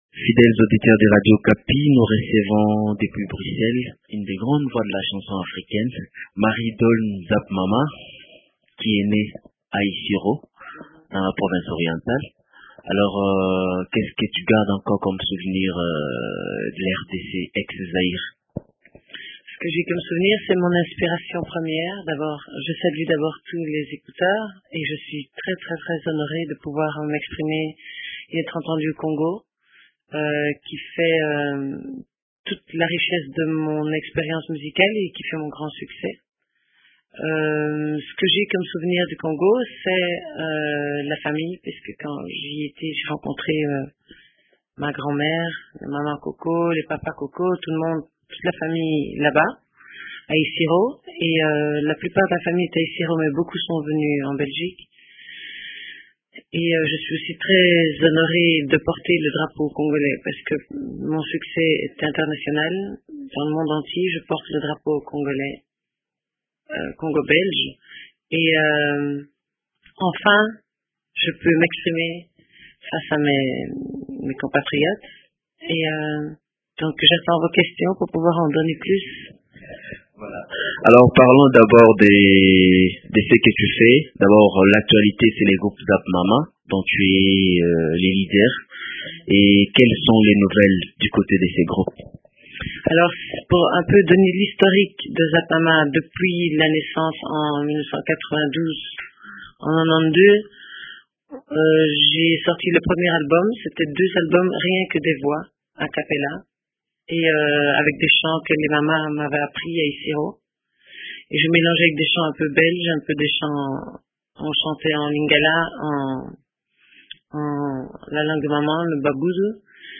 Dans un entretien